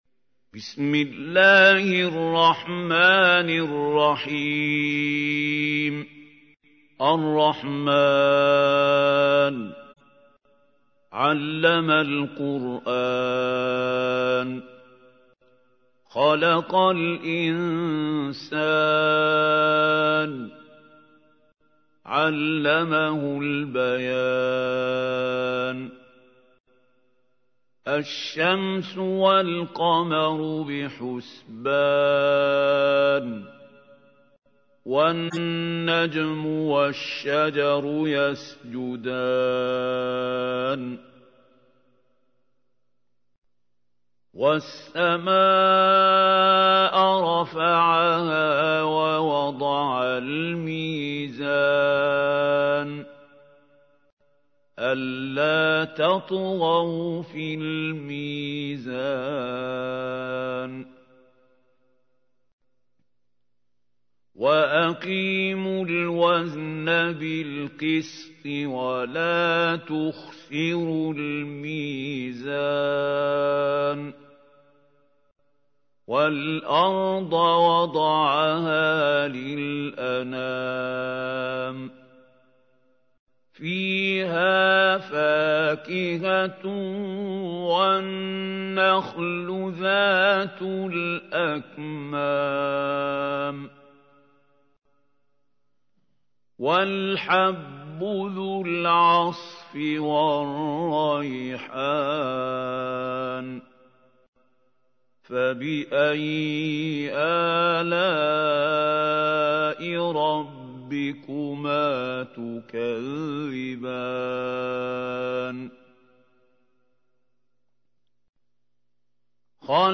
تحميل : 55. سورة الرحمن / القارئ محمود خليل الحصري / القرآن الكريم / موقع يا حسين